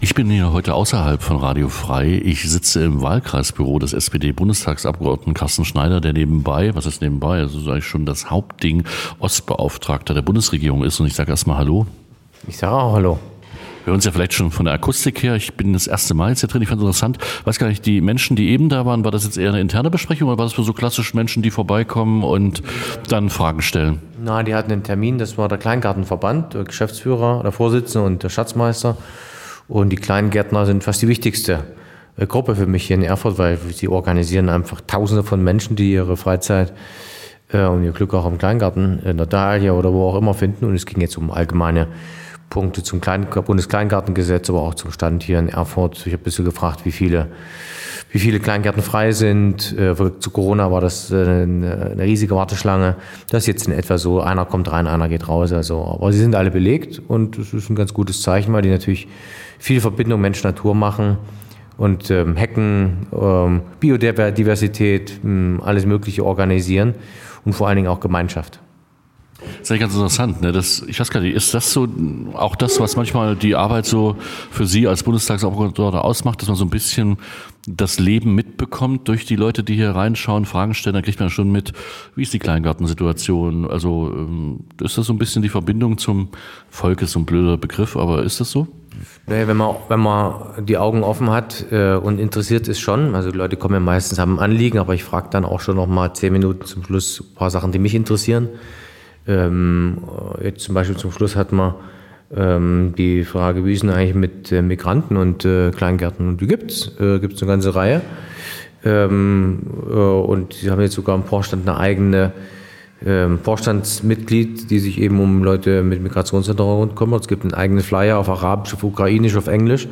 INTERVIEW Carsten Schneider.mp3